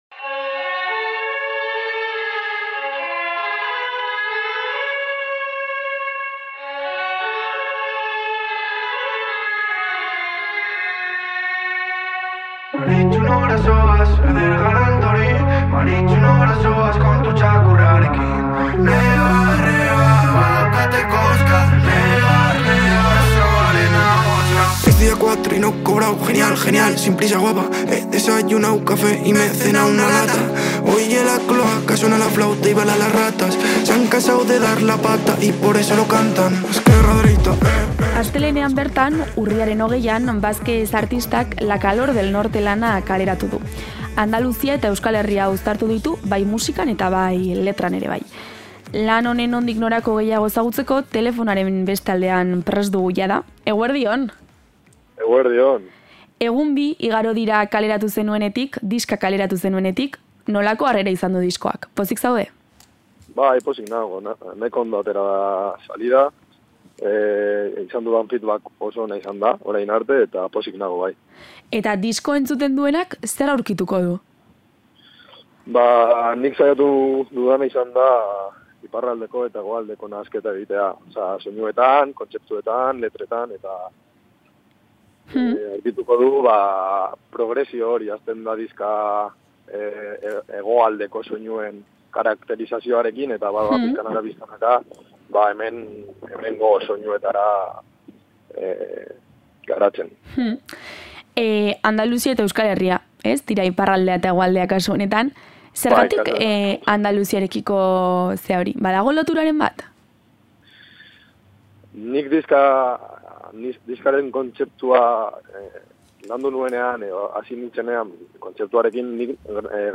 Andaluzia eta Euskal Herria uztartu ditu bai musikan, bai letretan. Berarekin aritu gara solasean eta kontatu digu letrak idazterakoan bi kontu izan dituela oinarrian; lehena, Granadan bizitzen egon zen bi urtean, eta, bigarrena, bere garapen pertsonala.